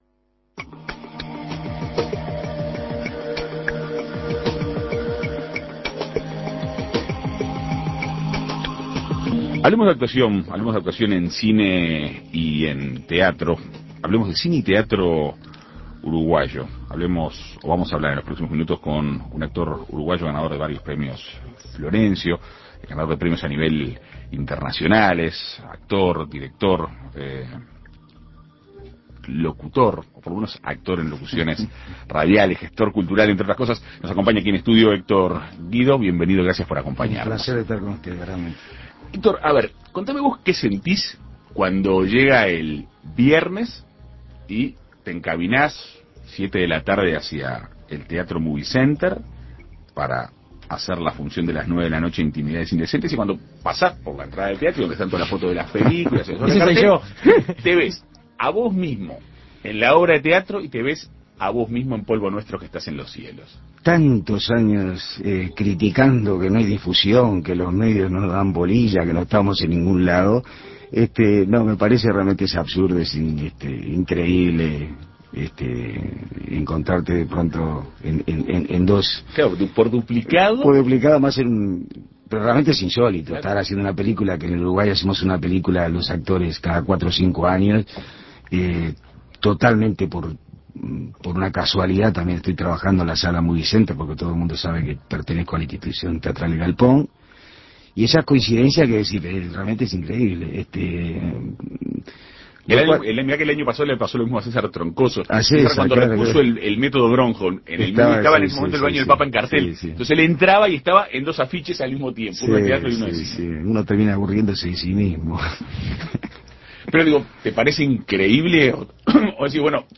El actor uruguayo y director de teatro Héctor Guido dialogó con En Perspectiva Segunda Mañana.